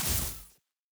pgs/Assets/Audio/Custom/Combat/Jump.wav at master
Jump.wav